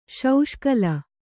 ♪ śauṣkala